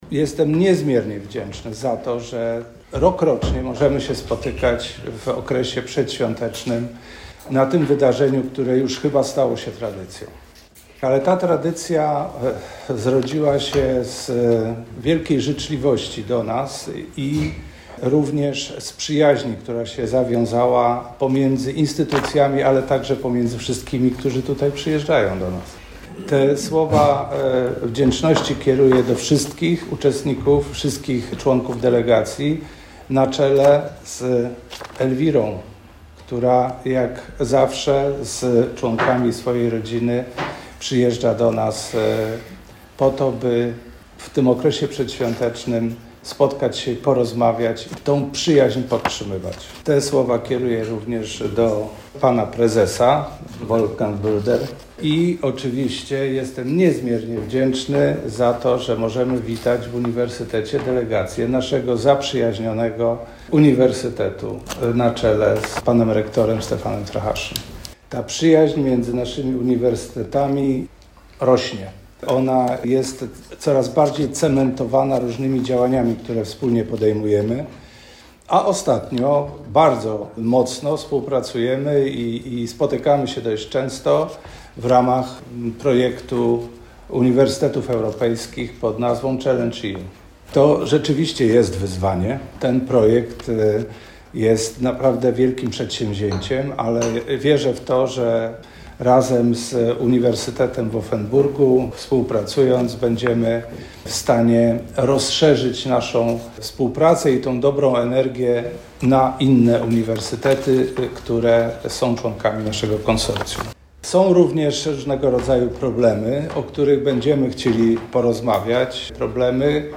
Posłuchajcie relacji z kolejnego spotkanie, które przed laty określono mianem Mostu Bożonarodzeniowego.